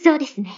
Enjoy some Weeb Anime Voices.